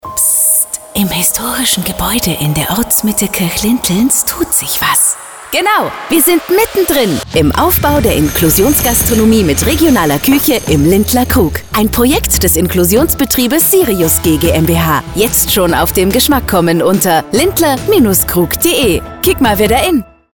Radiospot
Unser Radiospot, vielleicht ist er Ihnen ja schon bereits im Radio zu Ohren gekommen.